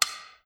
light-on.wav